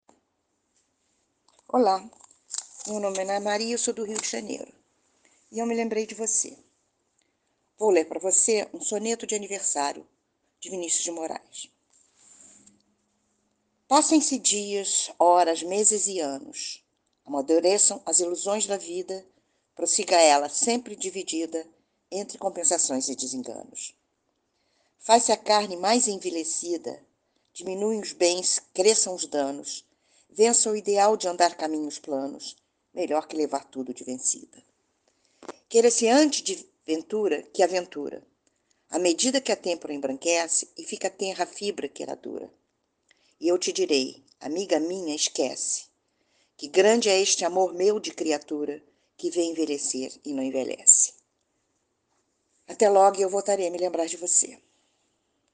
Poema Português